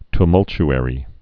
(t-mŭlch-ĕrē, ty-)